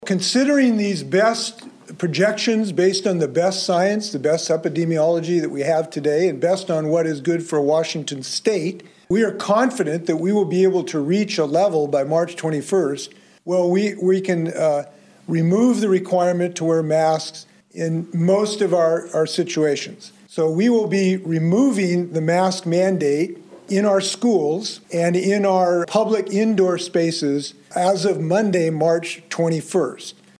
Washington Governor Jay Inslee held a press conference this afternoon regarding mask mandates and the state’s response to covid-19. Inslee says that if the downward trend of coronavirus hospitalization admissions continues, certain mandates will be phased out in the near future.